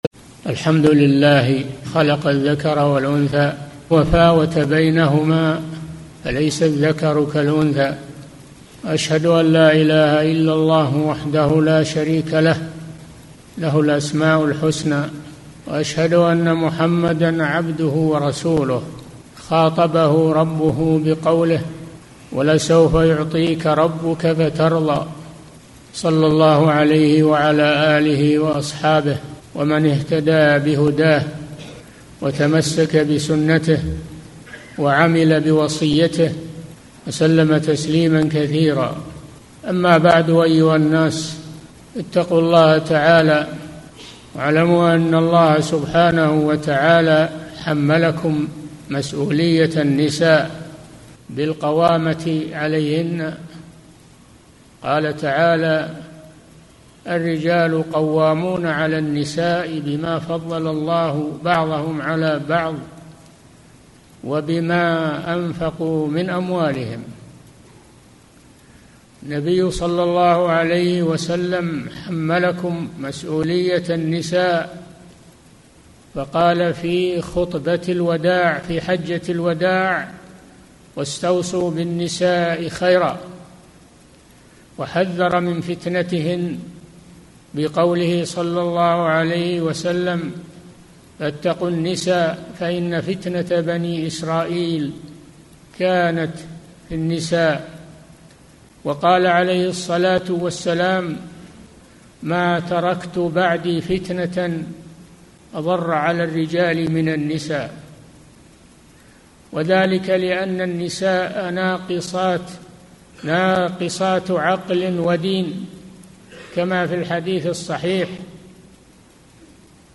الخطبة الأولى